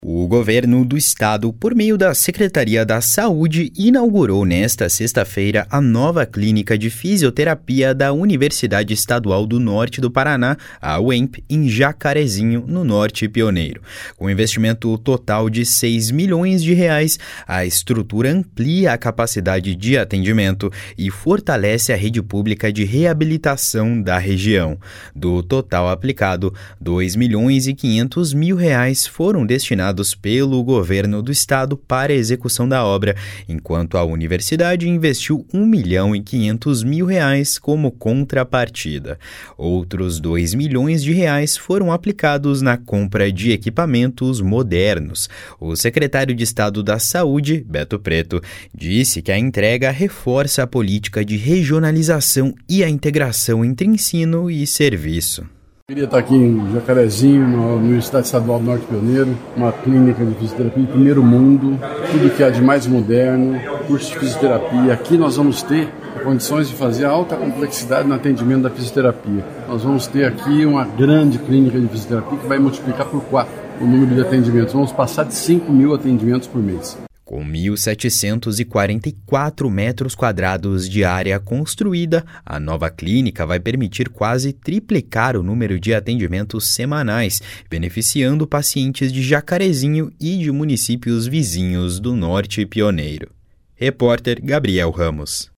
O secretário de Estado da Saúde, Beto Preto, disse que a entrega reforça a política de regionalização e a integração entre ensino e serviço. // SONORA BETO PRETO //